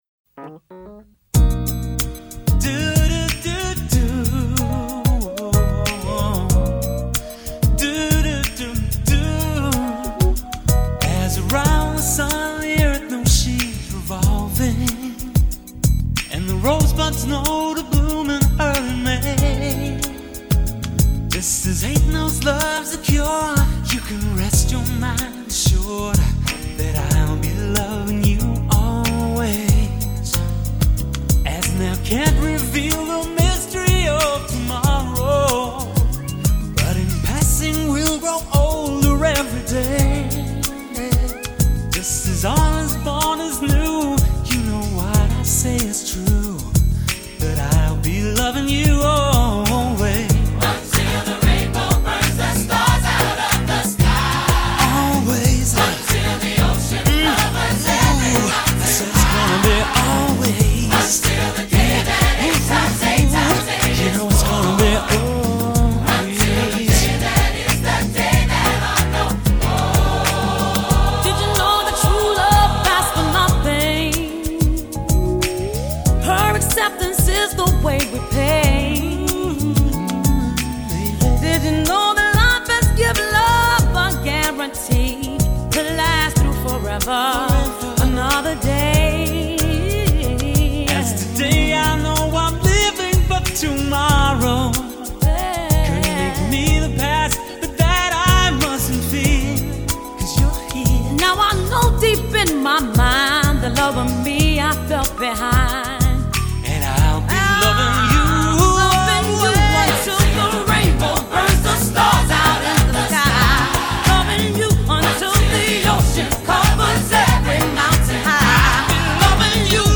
This extremely well-done cover